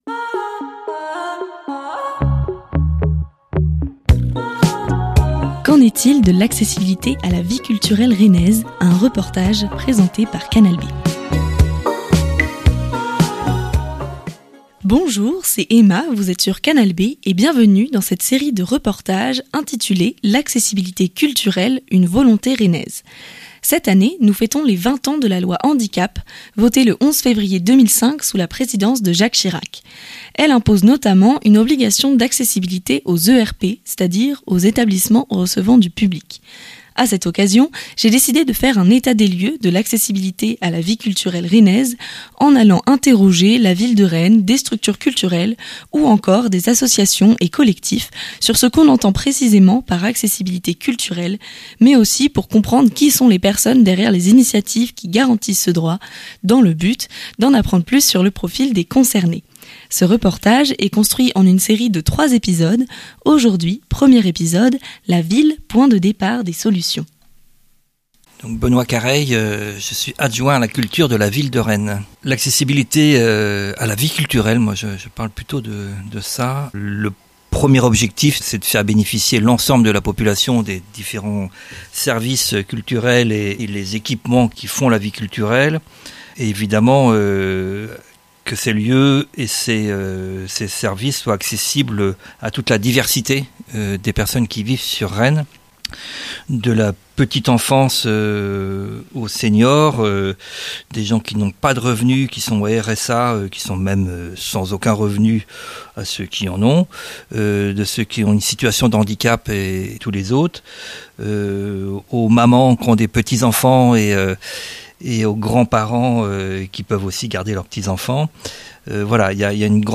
Emission spéciale | Episode 1 - La ville, point de départ des solutions :